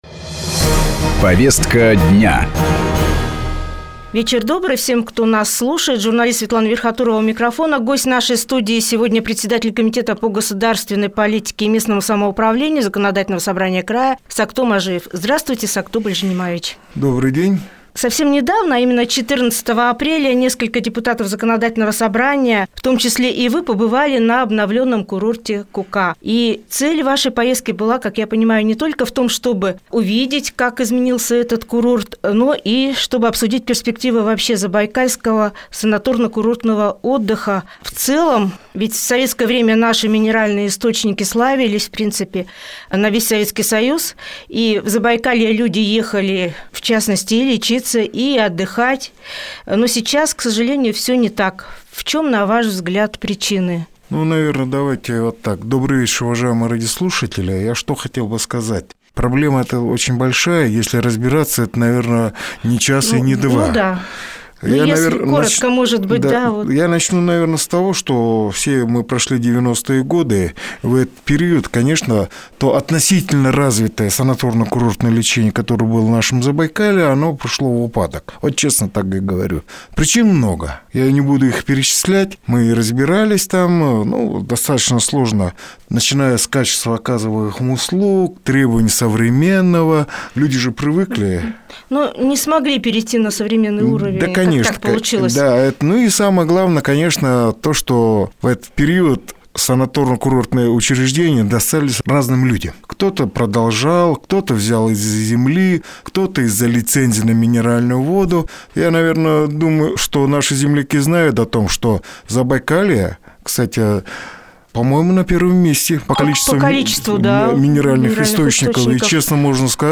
О развитии минеральных источников Забайкалья - интервью председателя комитета по госполитике и местному самоуправлению Сокто Мажиева